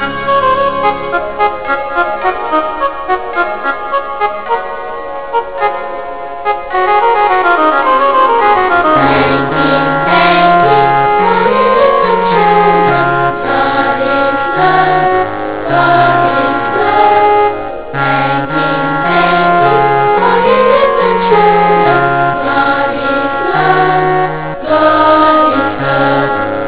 as a platform for the children to learn to sing hymns